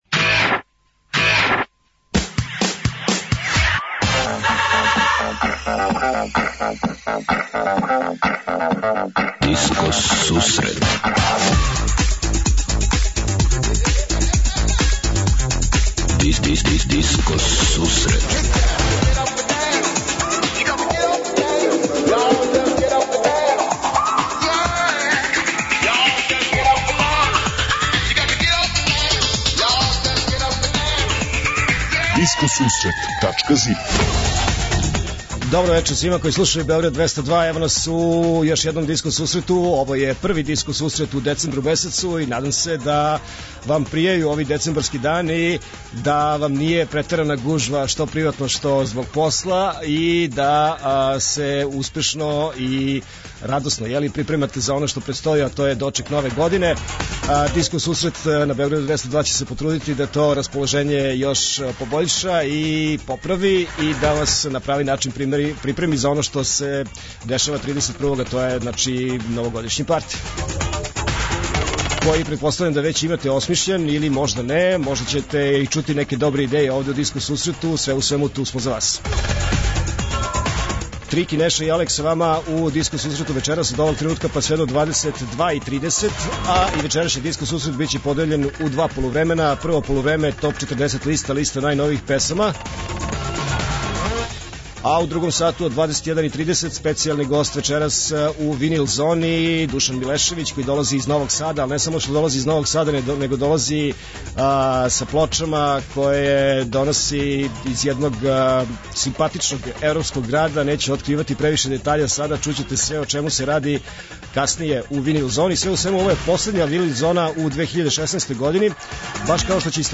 Од 20:30 Диско Сусрет Топ 40 - Топ листа 40 највећих светских диско хитова.
преузми : 28.69 MB Discoteca+ Autor: Београд 202 Discoteca+ је емисија посвећена најновијој и оригиналној диско музици у широком смислу, укључујући све стилске утицаје других музичких праваца - фанк, соул, РнБ, итало-диско, денс, поп.
Слушаоци, пријатељи и уредници Диско сусрета за вас пуштају музику са грамофонских плоча. Извештај са берзе плоча у Утрехту.